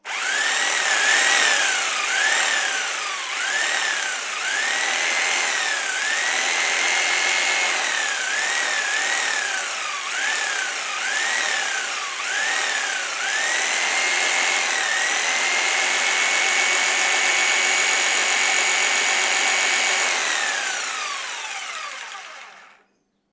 Sierra de calar
Sonidos: Industria